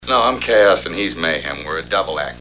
Mel Quote